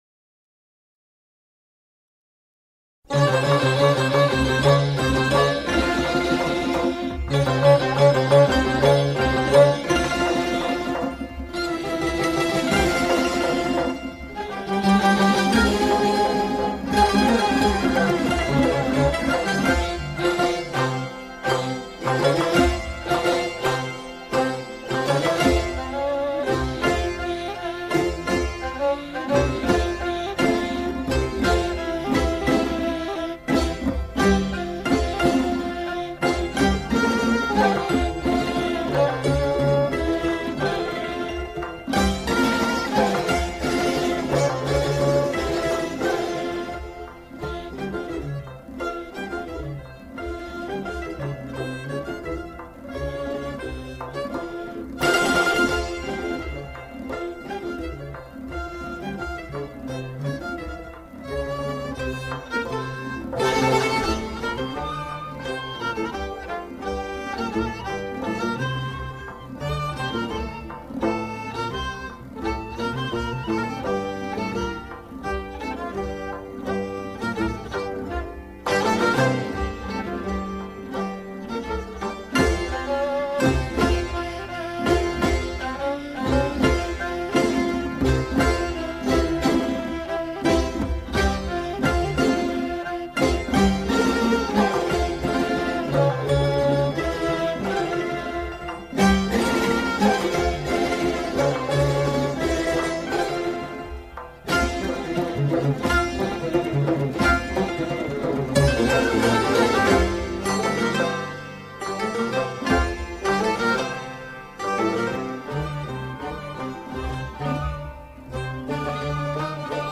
بی‌کلام